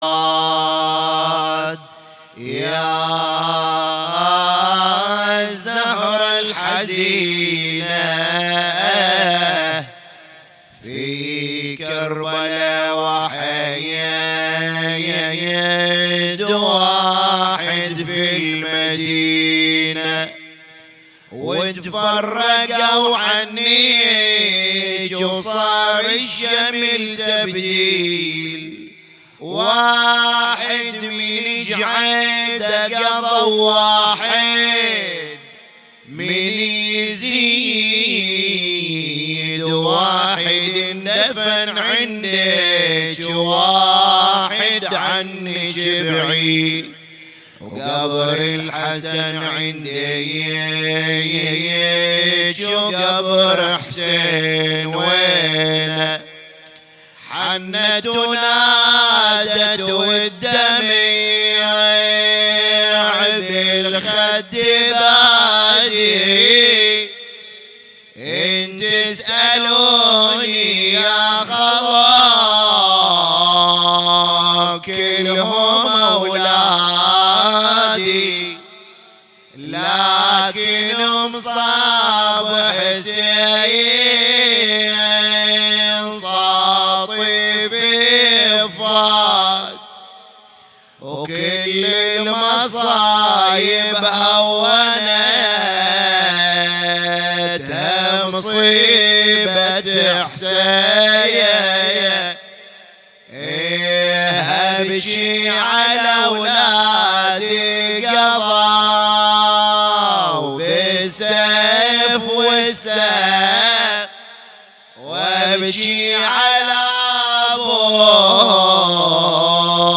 تغطية شاملة: مجلس ليلة 6 صفر ضمن الليالي الحسنية 1441هـ
تغطية صوتية: